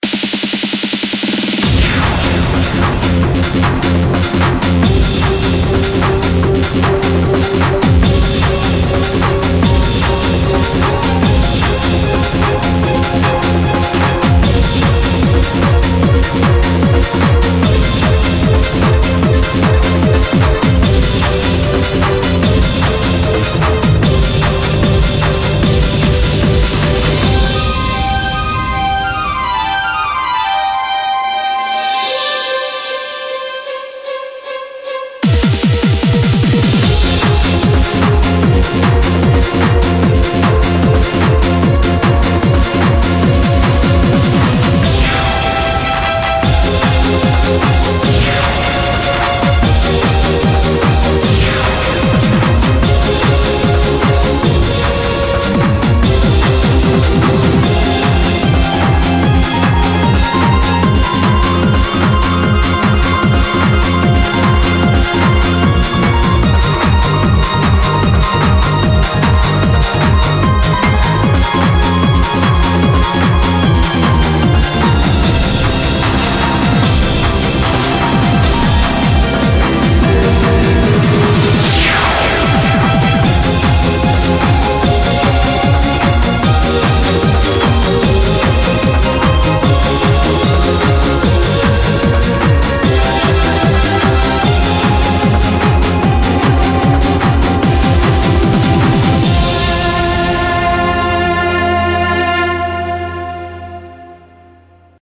BPM150
MP3 QualityMusic Cut